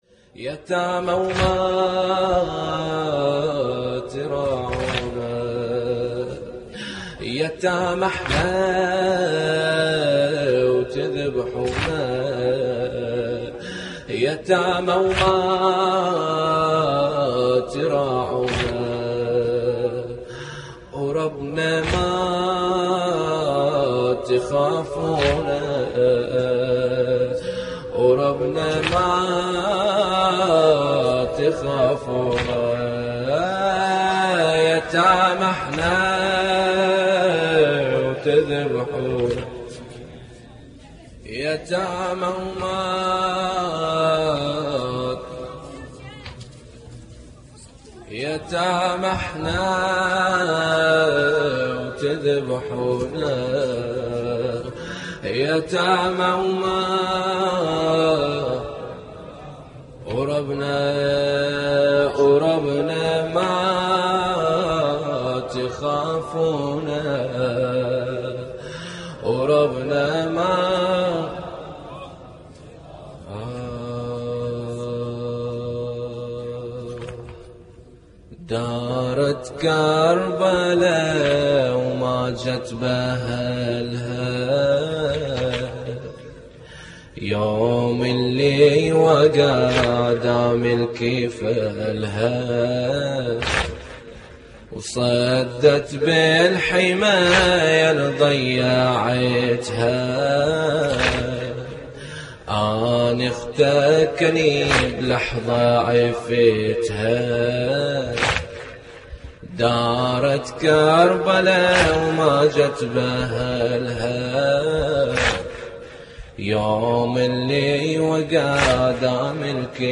اللطميات الحسينية